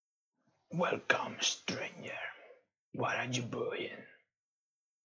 Open Vendor Dialog Line.wav